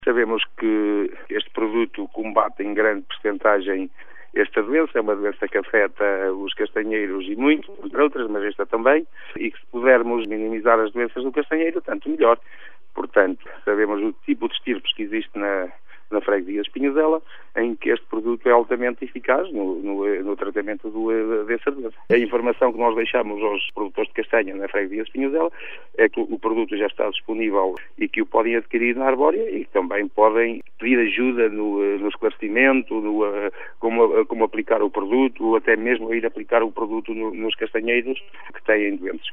O presidente da junta de freguesia de Espinhosela mostra-se satisfeito por ter sido possível conseguir um produto que evita prejuízos a muitos produtores de castanha da aldeia.